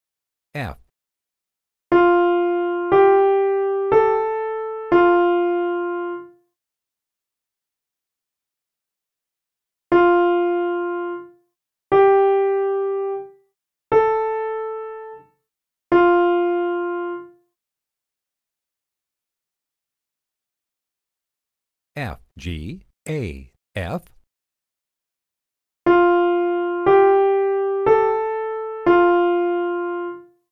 • “Easy”: Short melodies which always start and end on the root (tonic) note of the scale.
The Note Names tracks also announce the key note before each melody, so you can get your bearings on your instrument, ready to try playing back what you heard.
These are similar, but you will hear the melody, then a short pause for you to think about it, then the melody again slower. Then after a pause, the answer is given so you can check if you got it right, and finally the melody is played again.
Easy Testing Melody